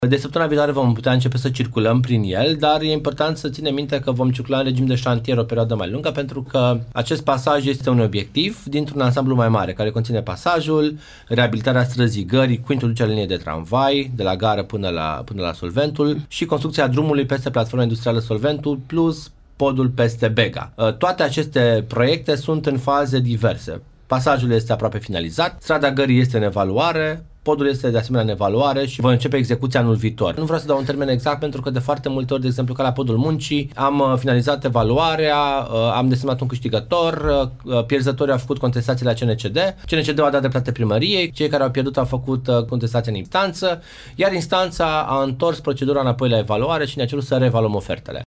Au fost finalizate probele inclusiv cele la iluminatul public, iar pasajul va fi deschis traficului în regim de șantier, spune viceprimarul Ruben Lațcău.